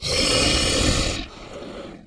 58aea60d01 Divergent / mods / Soundscape Overhaul / gamedata / sounds / monsters / bloodsucker / idle_1.ogg 16 KiB (Stored with Git LFS) Raw History
idle_1.ogg